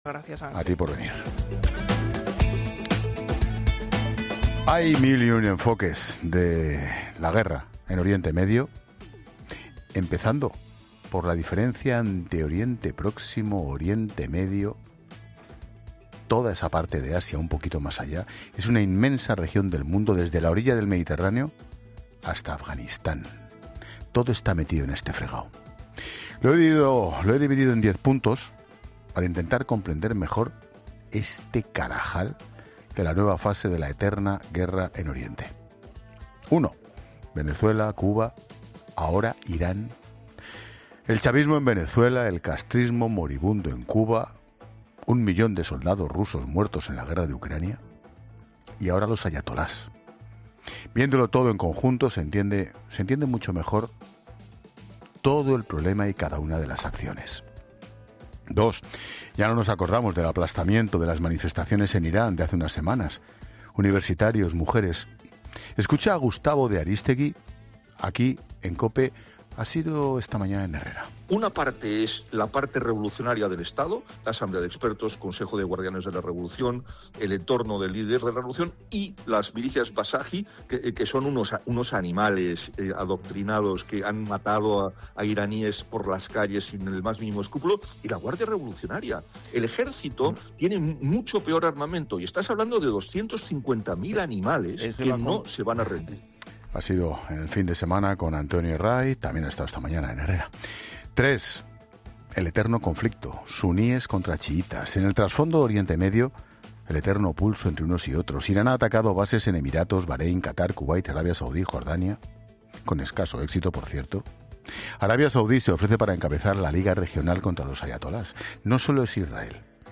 El comunicador Ángel Expósito ha ofrecido en 'La Linterna' de COPE un análisis detallado sobre la nueva escalada de la guerra en Oriente Medio, desgranando el conflicto en diez puntos clave.
El comunicador ha rescatado un vídeo de Pablo Iglesias en el que el entonces líder de Podemos justificaba su relación con el régimen iraní.
Tras emitir estas palabras, Expósito ha concluido su análisis de forma tajante, conectando directamente la hemeroteca con la actualidad.